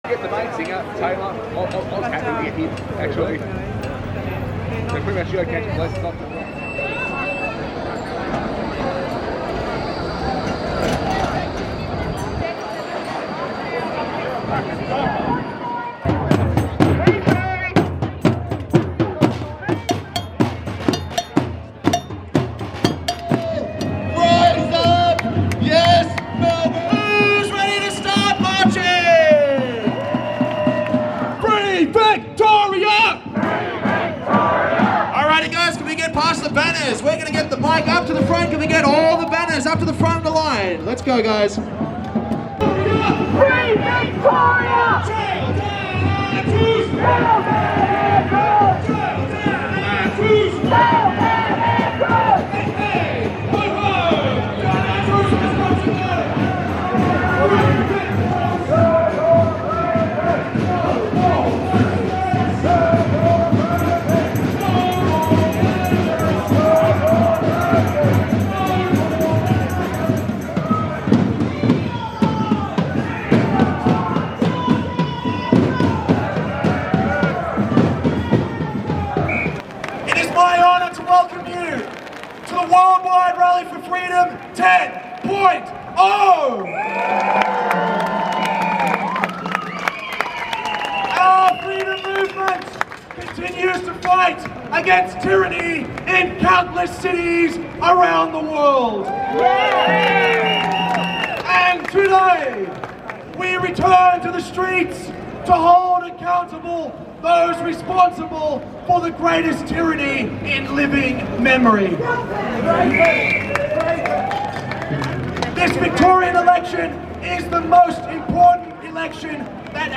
Melbourne Freedom Rally 19 November 2022